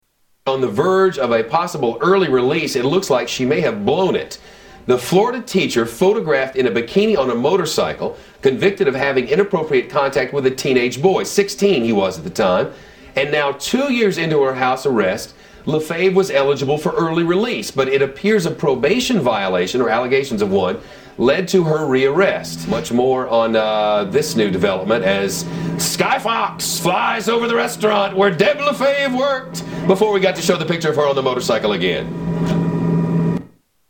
Tags: Media Shepard Smith News Anchor Shepard Smith The Fox Report News Anchor